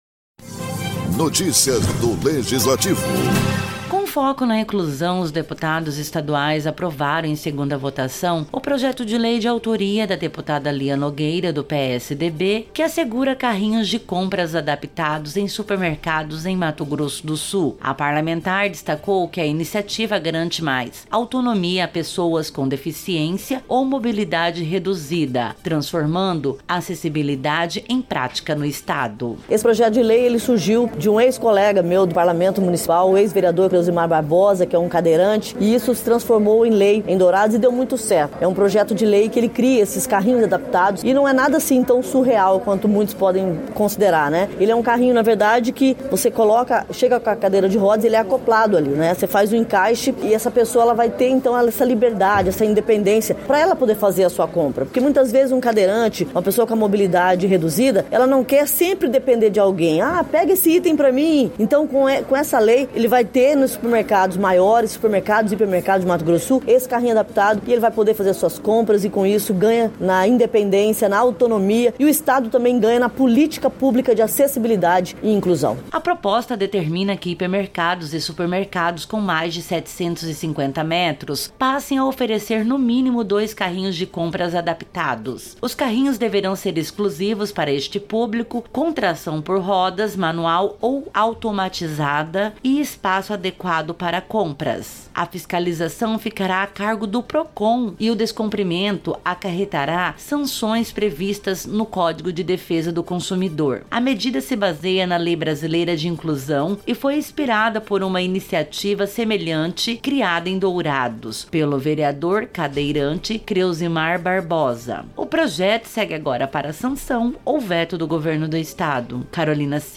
ALEMS aprova lei que garante carrinhos adaptados em supermercados